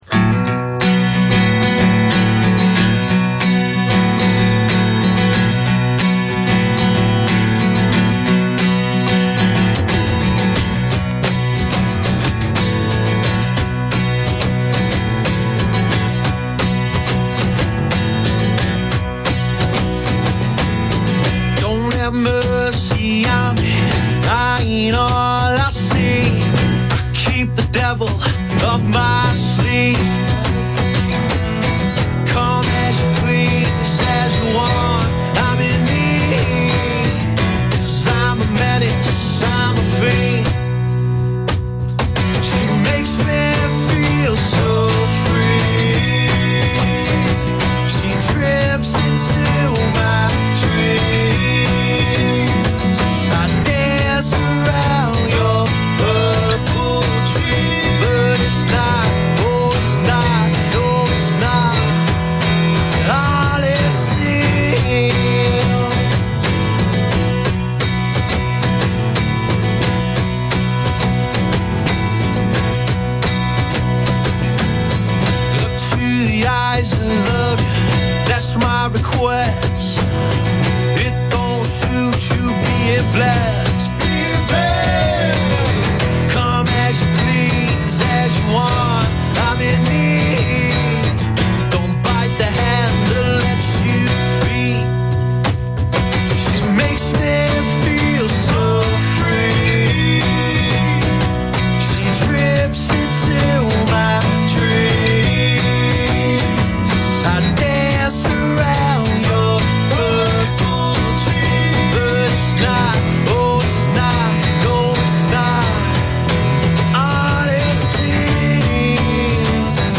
rock and roll outfit
in-your-face five-piece